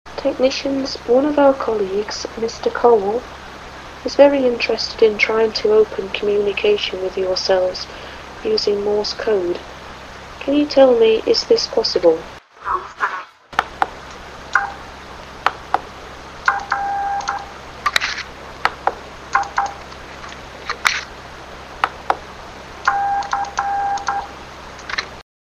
EVP MORSE – 2 – The Amazing Portal